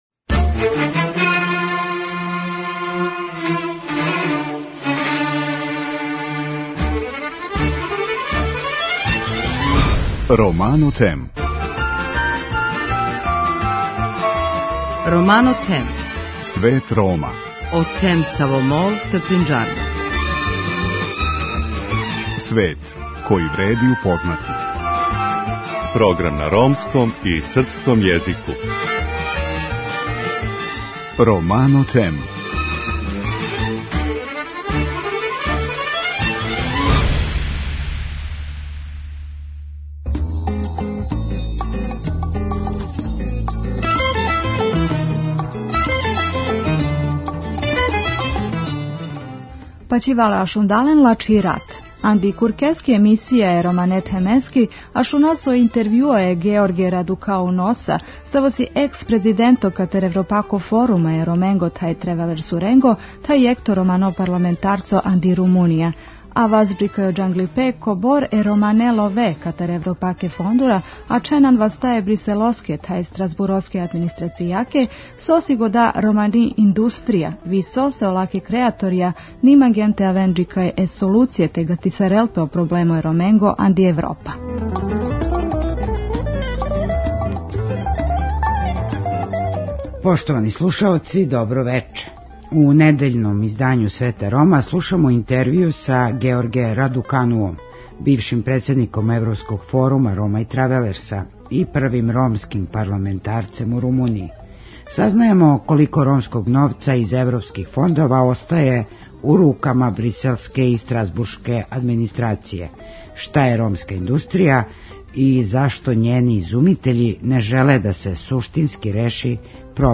У недељном издању Света Рома слушамо интевјуу са Георгијем Радукануом бившим председником Европског форума Рома и Тревелерса и првим ромским парламентарцем у Румунији.